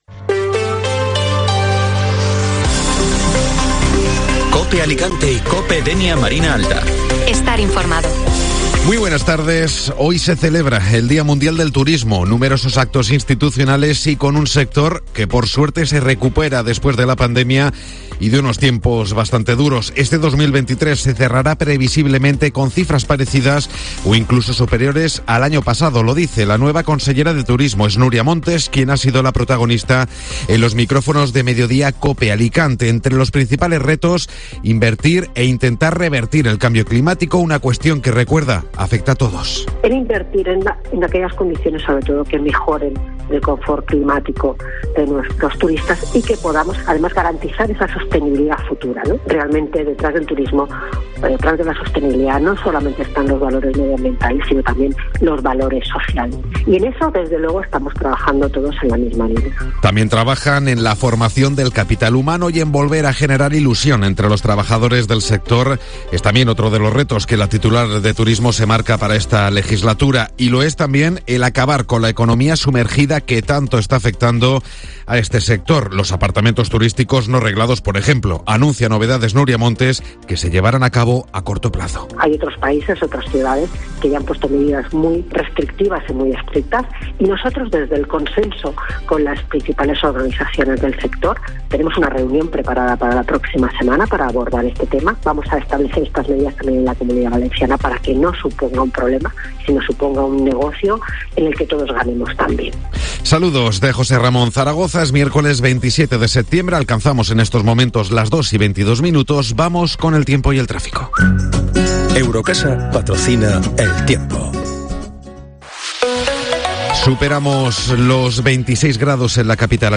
Informativo Mediodía COPE Alicante (Miércoles 27 de septiembre)